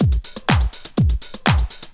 Track Master Beat 2_123.wav